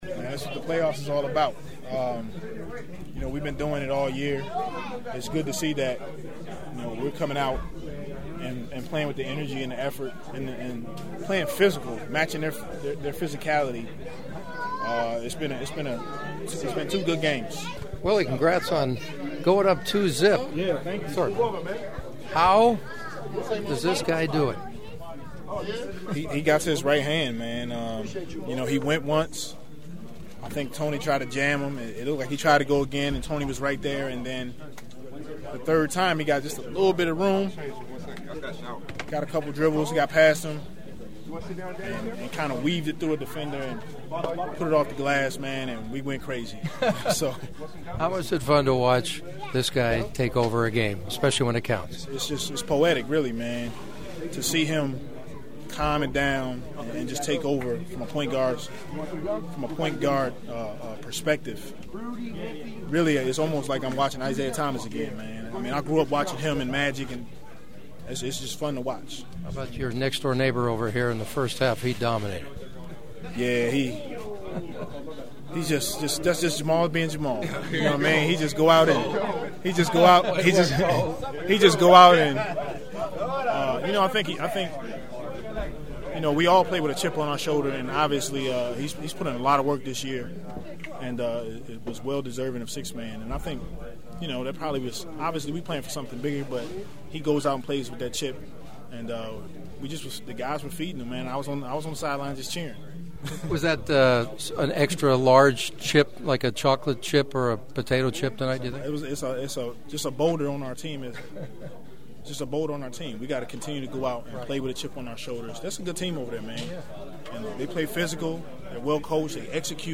The following are my postgame Clipper interviews along with several preview thoughts for games 3 and 4 in Memphis on Thursday and Saturday (which you can hear of course on KFWB Newstalk 980).
A few fun notes during my postgame locker room sound gathering…while I was interviewing Willie Green, his nextdoor (locker) neighbor Chris Paul walked up and was listening in to our chat.